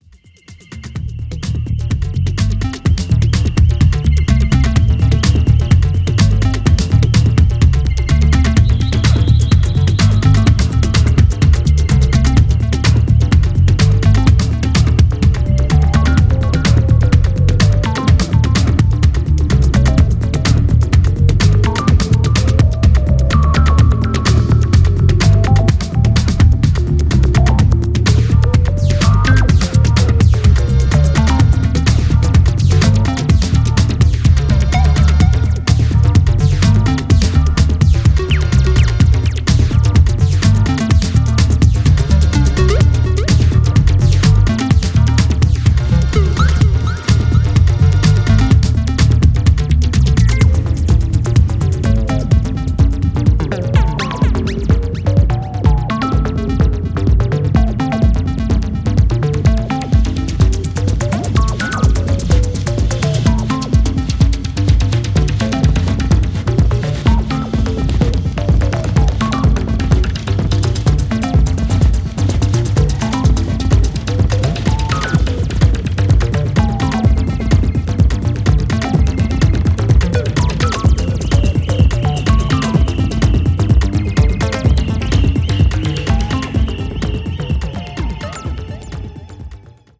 Abstract, Ambient